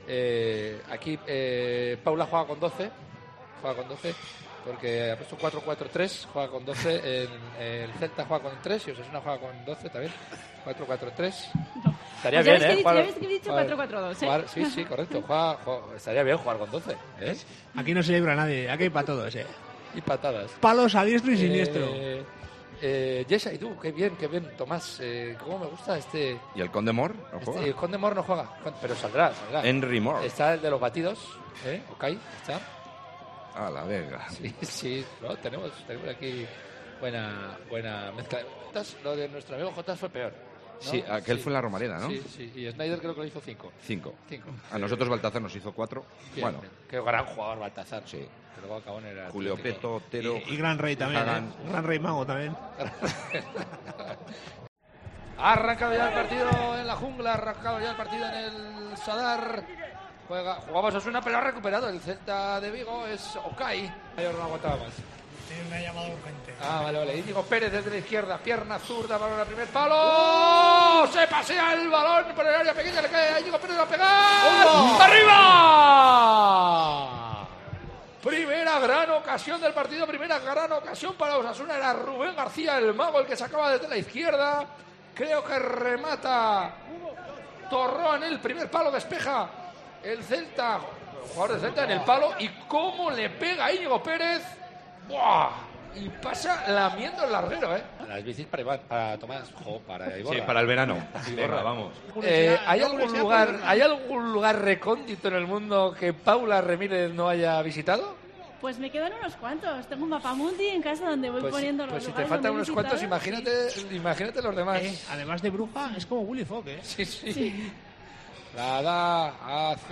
TERTULIÓN ROJILLO